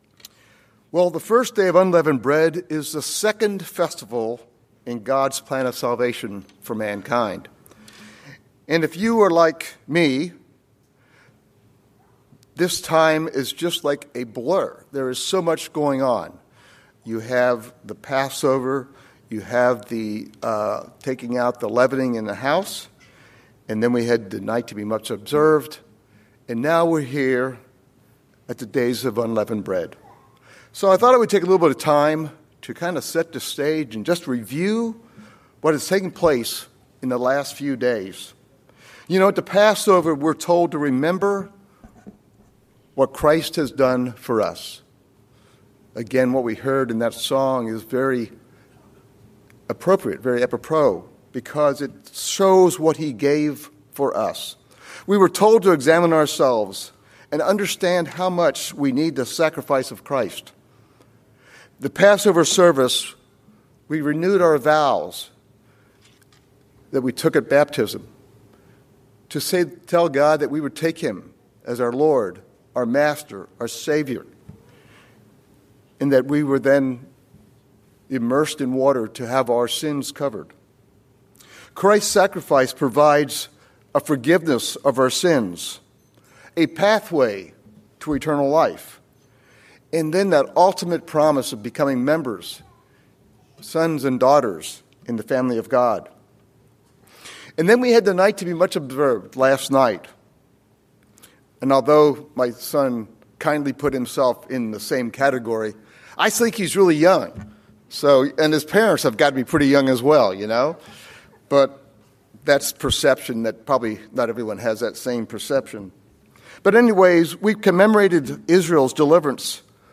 Sermons
Given in Atlanta, GA Buford, GA